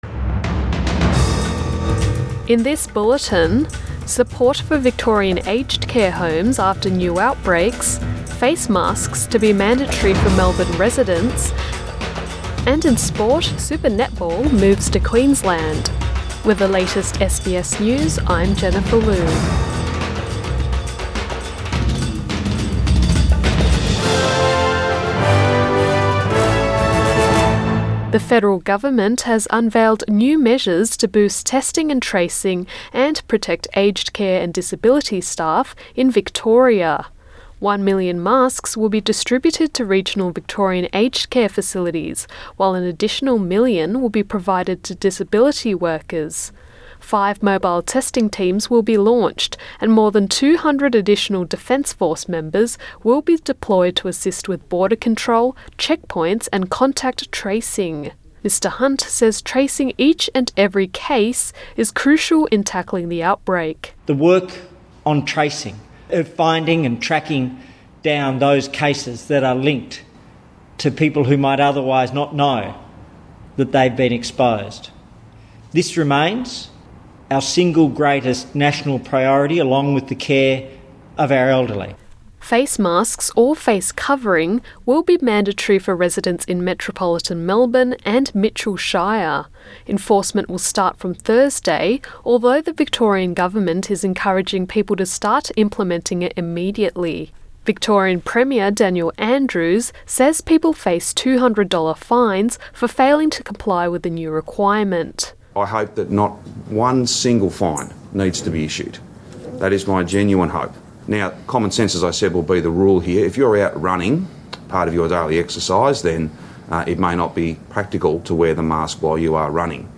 PM bulletin 19 July 2020